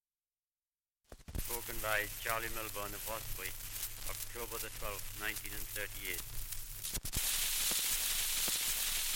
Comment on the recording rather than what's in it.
78 r.p.m., cellulose nitrate on aluminium.